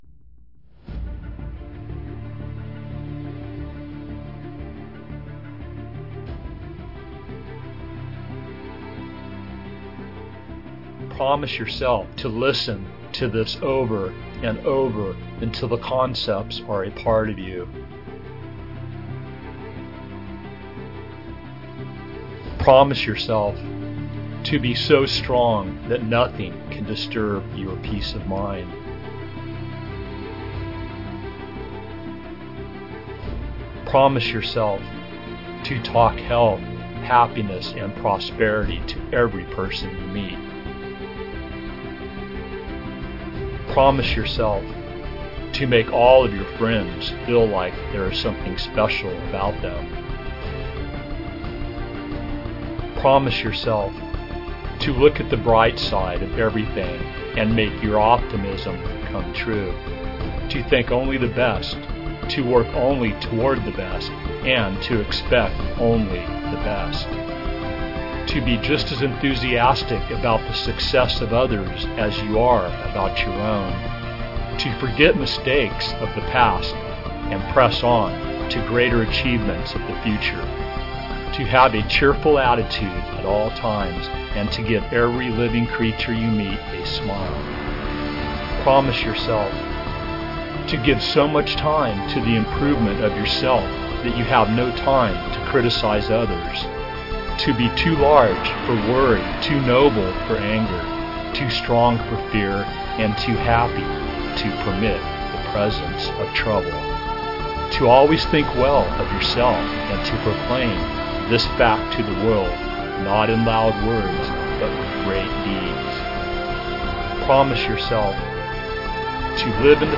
The Promise Yourself Motitation is 20 minutes long with the message or “thought code” being repeated numerous times.